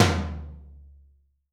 TOM 3H.wav